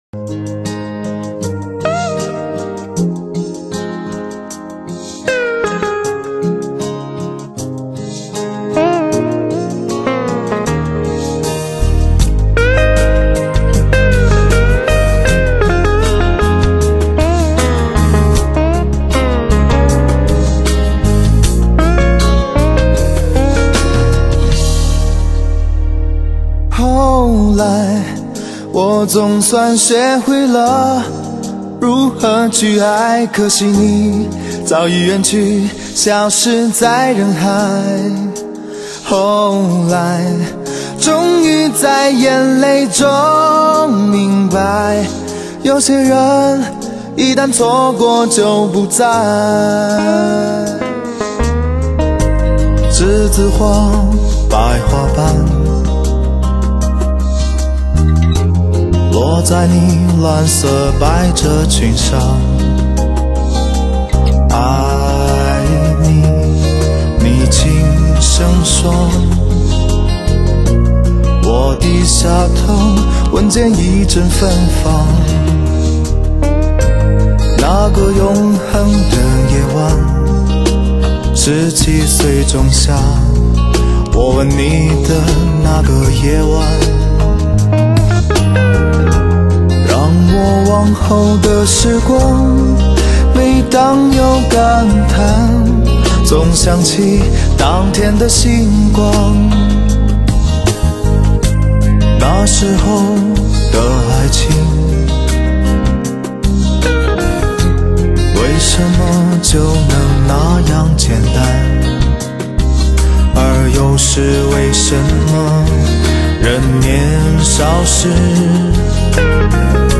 岁月流声动记忆的经典回味，HI-FI白金唱片，颗粒分明的通透音色，试听
发烧友票选的最爱POP怀旧金曲。
极富视听效果的发烧靓声，德国版HD高密度24bit数码录音。